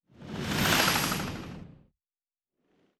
pgs/Assets/Audio/Sci-Fi Sounds/Movement/Fly By 04_1.wav at master
Fly By 04_1.wav